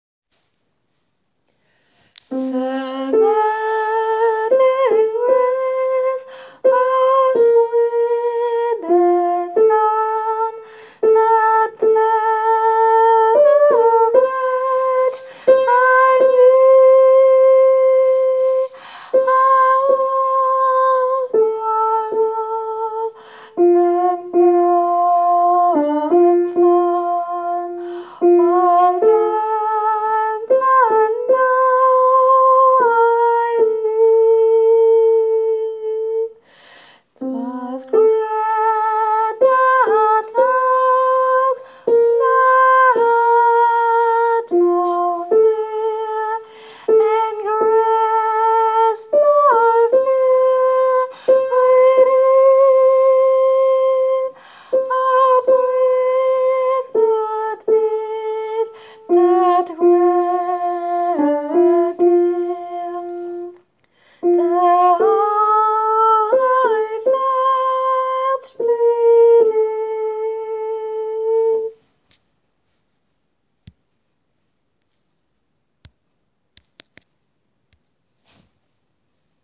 Désolée pour mon anglais “yaourt” !
amazing-vx-haute.wav